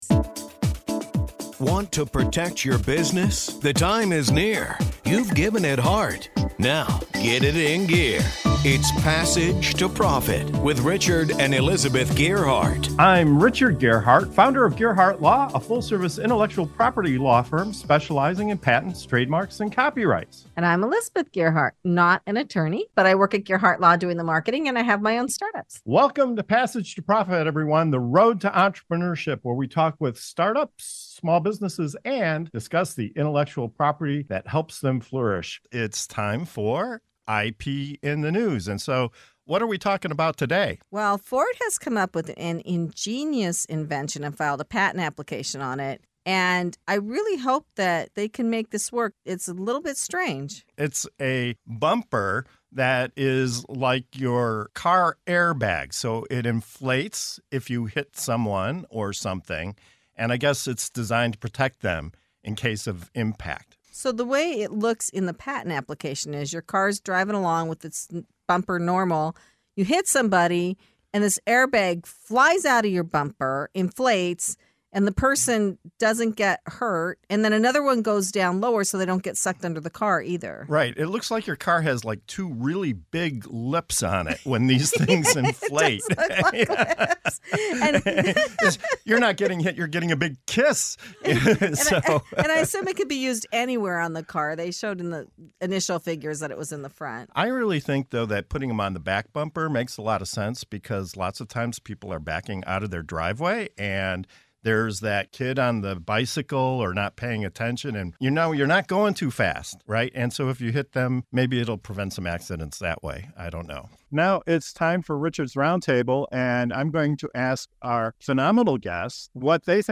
Our special guests share their insights, raising questions about the innovation's consumer appeal and functionality.